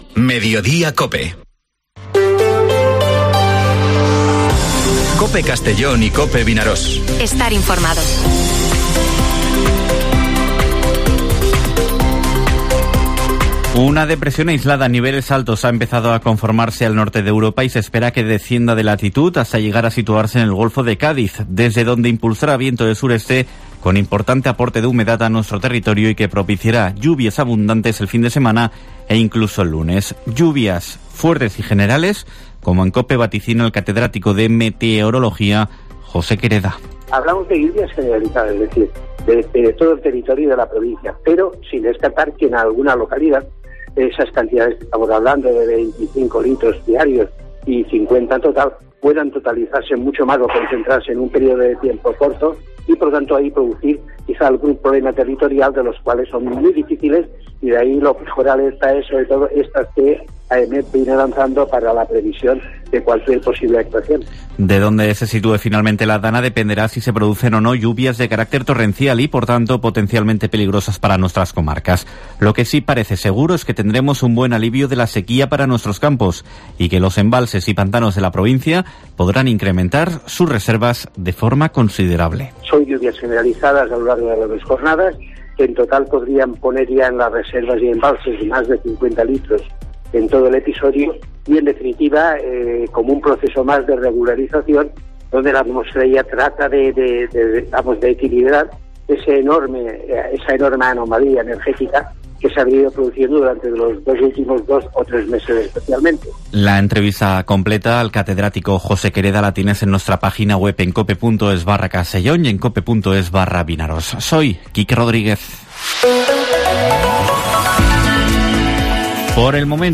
Informativo Mediodía COPE en la provincia de Castellón (31/08/2023)